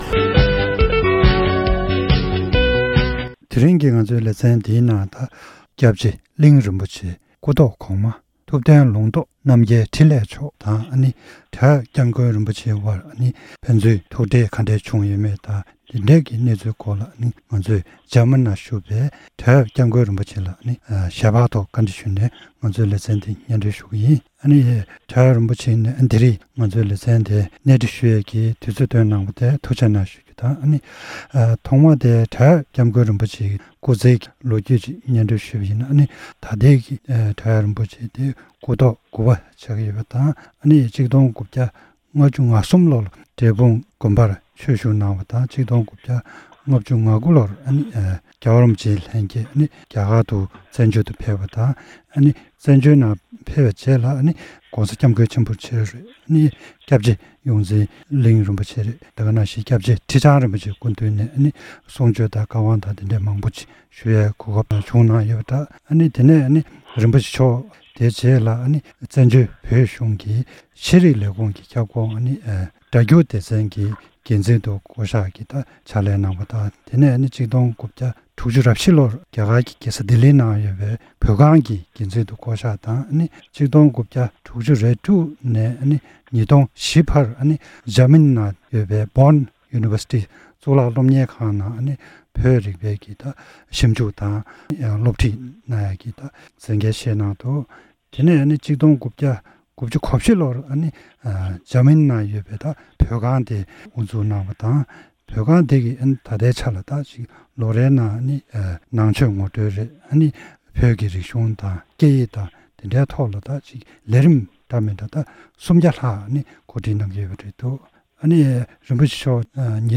གནས་འདྲི་ཞུས་པ་ཞིག